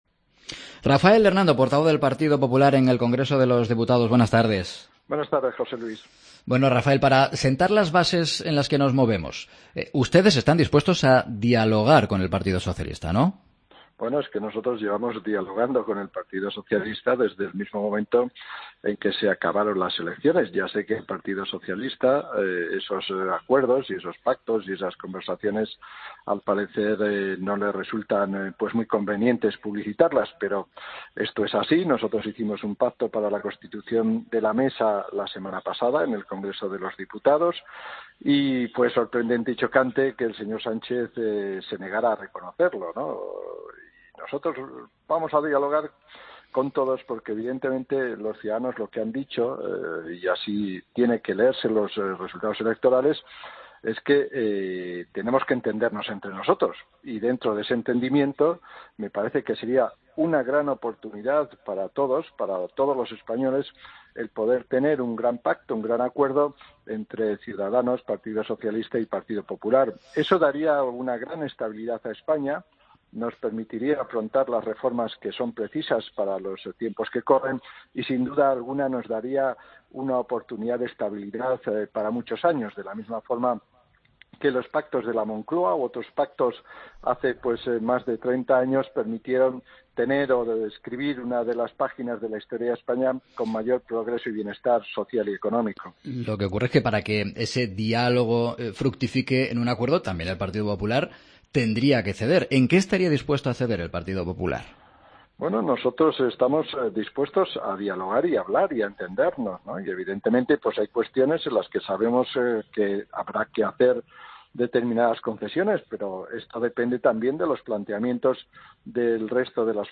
Escucha la entrevista a Rafael Hernando en 'Mediodía COPE'